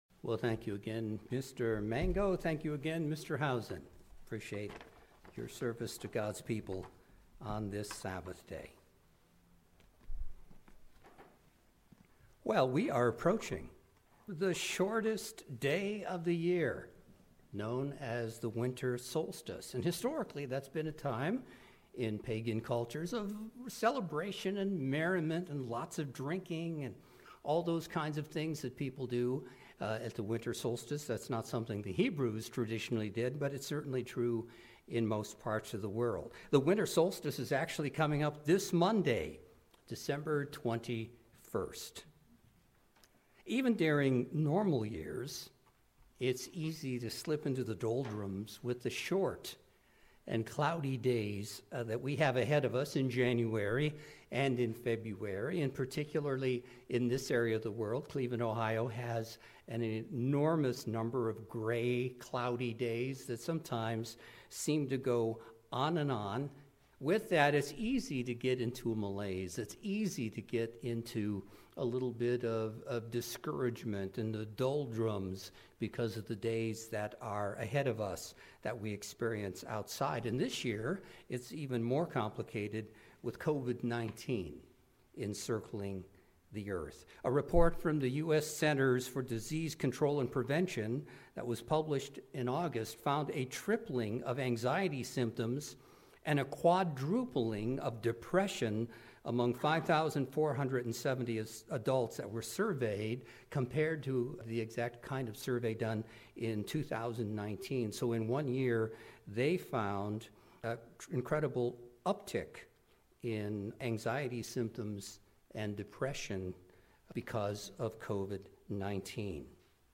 For this Sermon, I would like to offer 7 things a Christian can do every day to be your best.